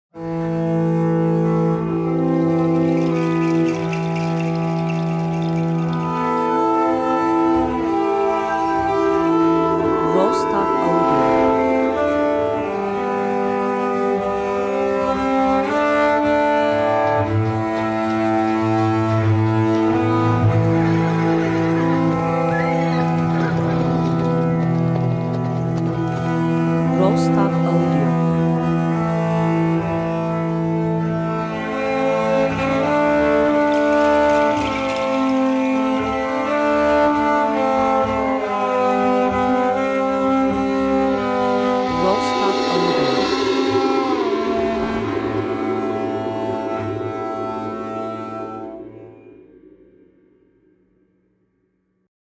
enstrümantal müzik
duygusal epik epic